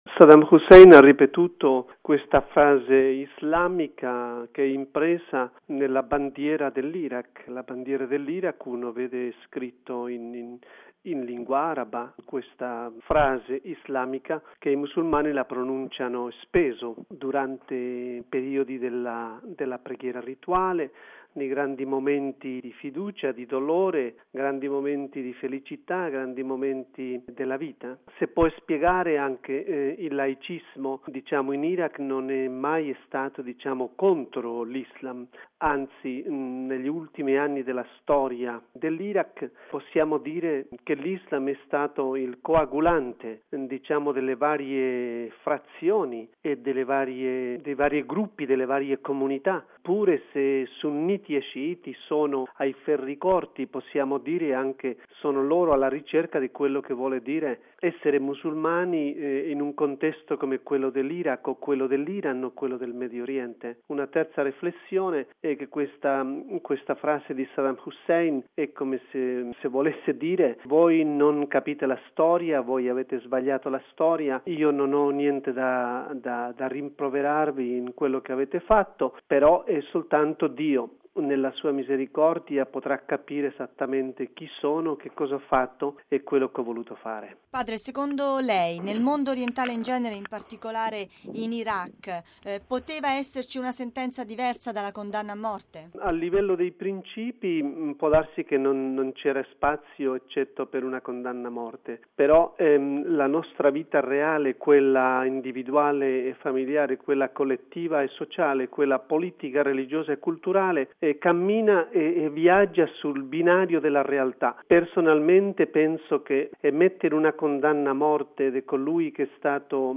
Nell’intervista